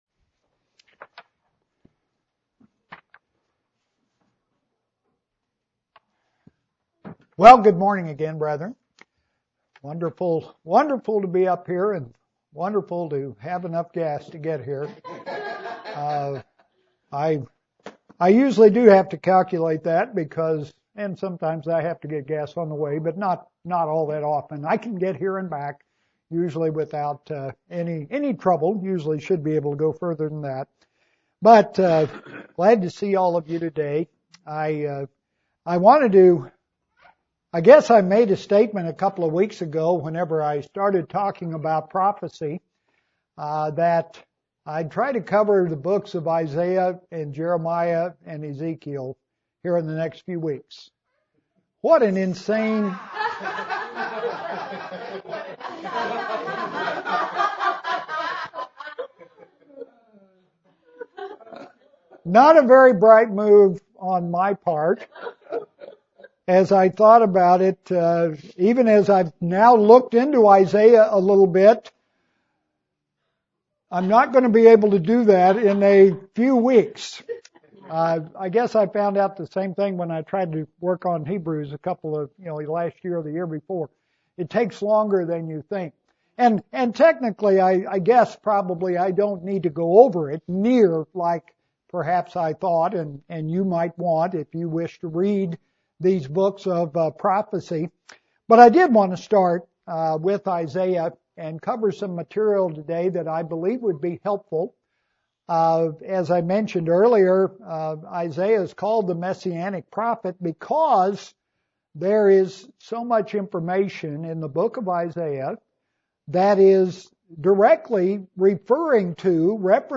Introduction and summary to the Book of Isaiah. First of several sermons on Isaiah.